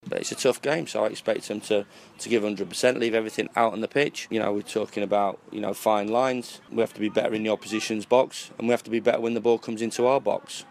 Sheffield United manager Chris Wilder gives his thoughts on facing Milwall in the League One encounter.